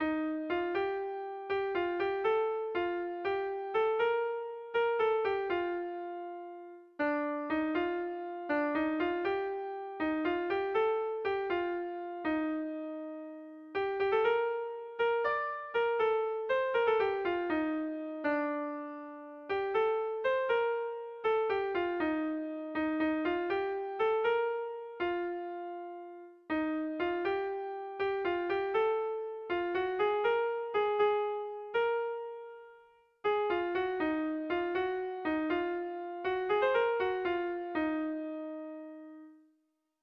Kontakizunezkoa
Hamabikoa, ertainaren moldekoa, 6 puntuz (hg) / Sei puntukoa, ertainaren moldekoa (ip)
ABDE....